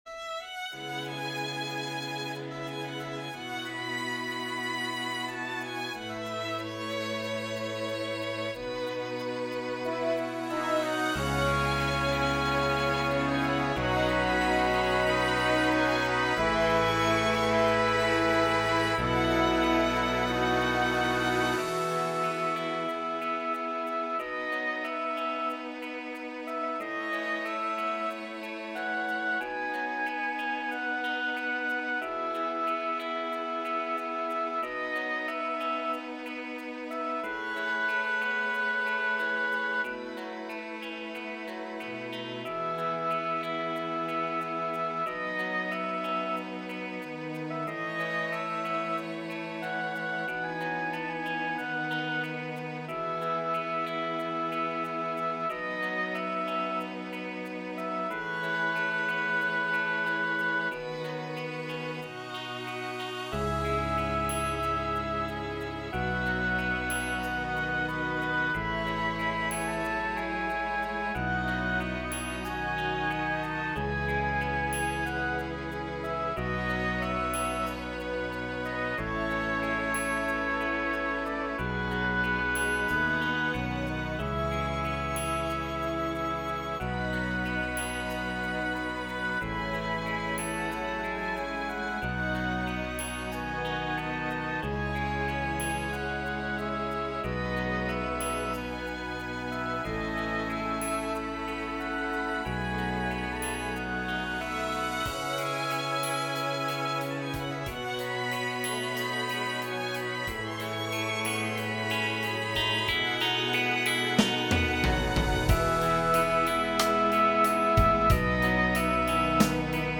結局映画の曲を聴き取りして作成したのだが，細部が聞き取れなかったため，私が勝手に副旋律を追加している．
機械再生